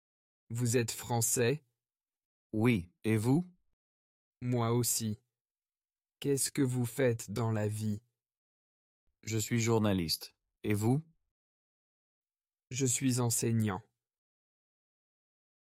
Dialogue – Identité et professions (Niveau A1)